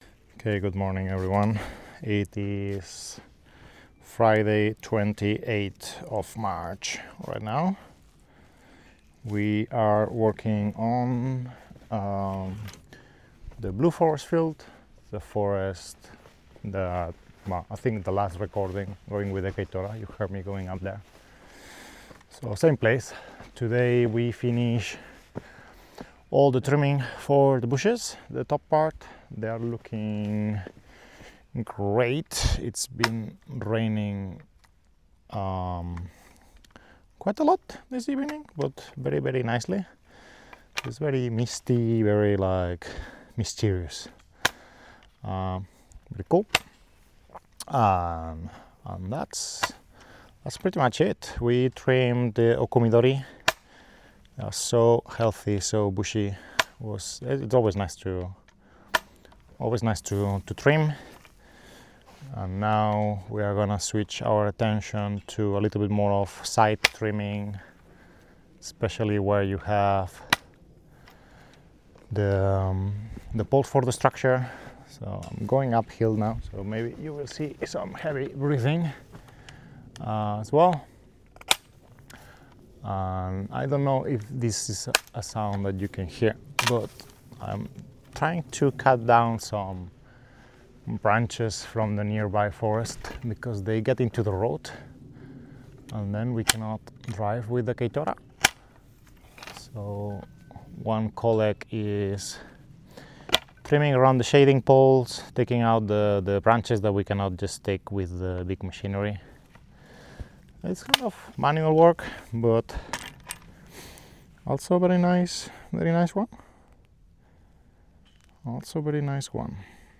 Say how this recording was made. On this episode, I record while clipping some branches out of the way, talk a bit about the upcoming harvest, and talk a bit about Awabancha.